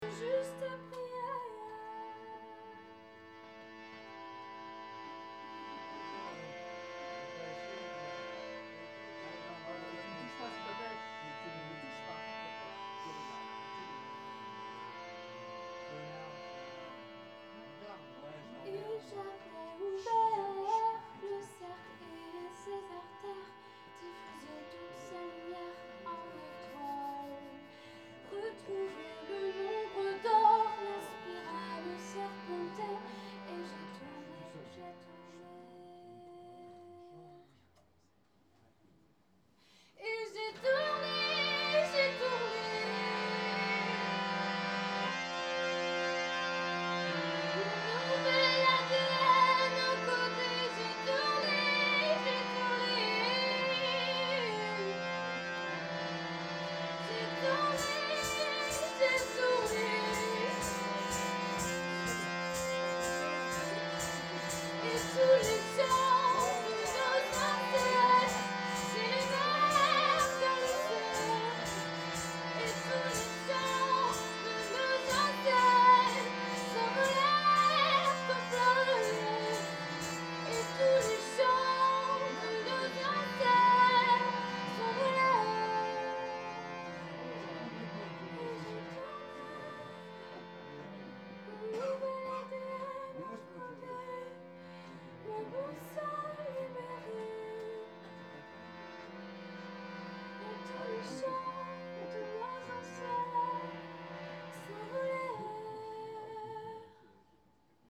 qui s’accompagne d’un étrange accordéon à clapet.
Aujourd’hui c’est un très bon flûtiste. Le public est hétéroclite, certains sont émerveillées, d’autres ont trop bu et s’engueulent.